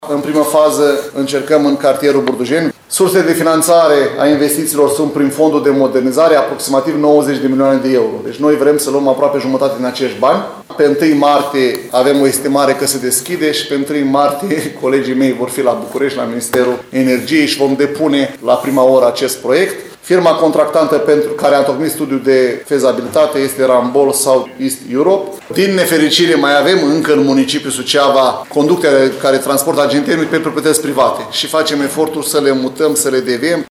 Viceprimarul LUCIAN HARȘOVSCHI a declarat că valoarea totală a investiției este de 47 milioane euro, având în vedere gradul avansat de uzură a rețelelor, instalațiilor și echipamentelor.